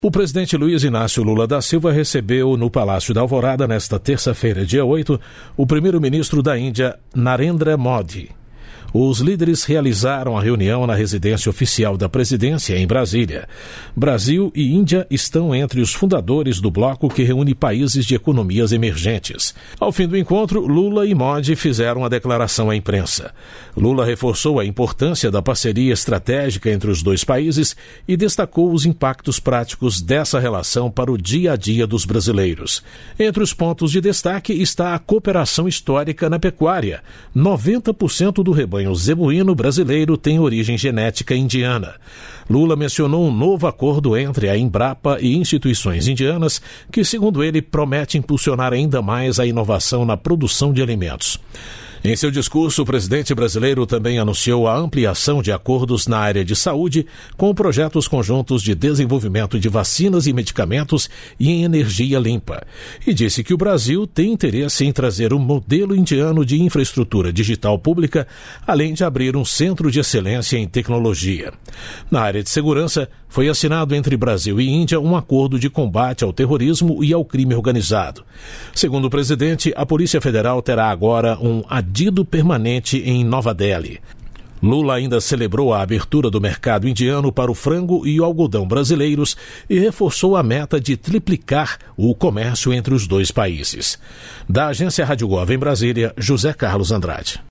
Ao fim do encontro, Lula e Modi fizeram uma declaração à imprensa. Lula reforçou a importância da parceria estratégica entre os dois países e destacou os impactos práticos dessa relação para o dia a dia dos brasileiros.